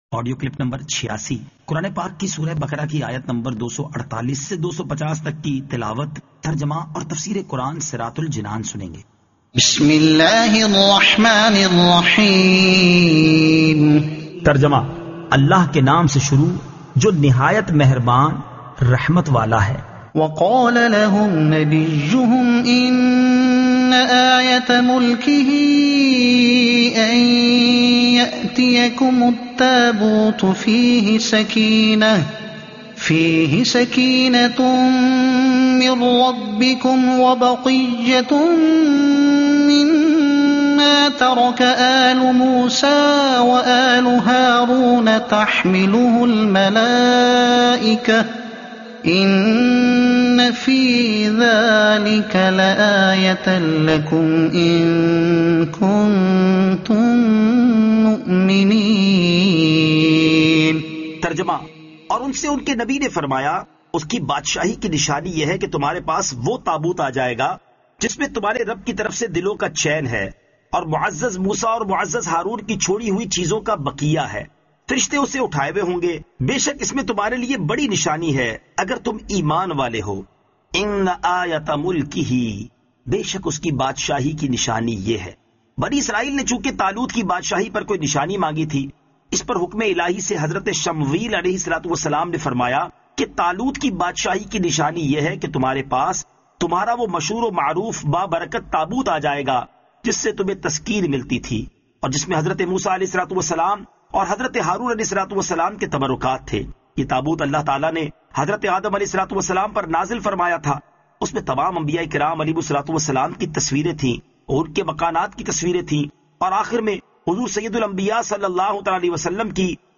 Surah Al-Baqara Ayat 248 To 250 Tilawat , Tarjuma , Tafseer